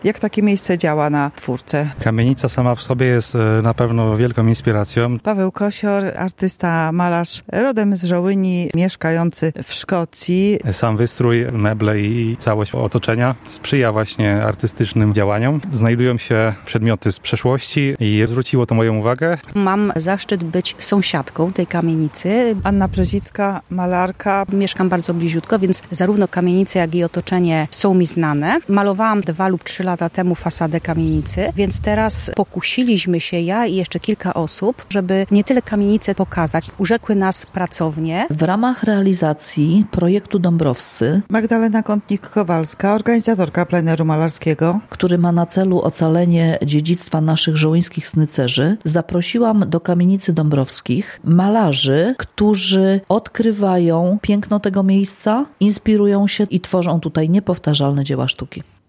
W Kamienicy Dąbrowskich była z mikrofonem